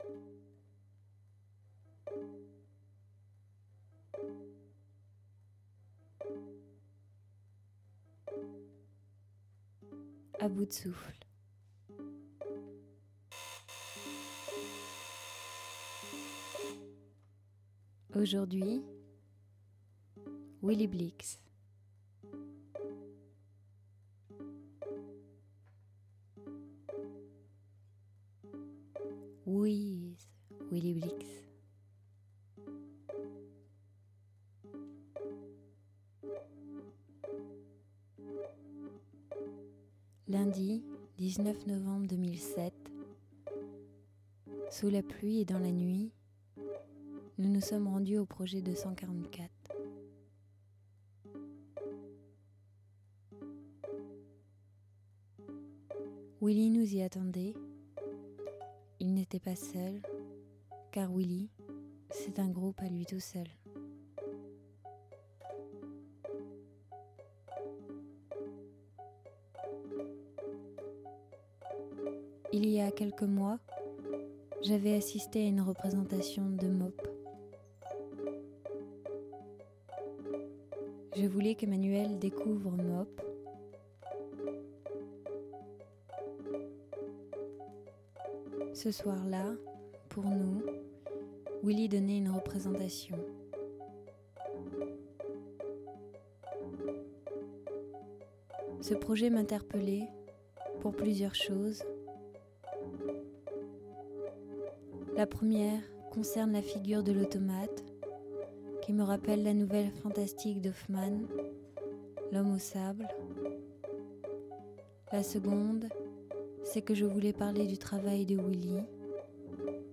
Site Internet du collectif A Bout De Souffle proposant le t�l�chargement de cr�ations sonores mais aussi d'entretiens et de reportages radiophoniques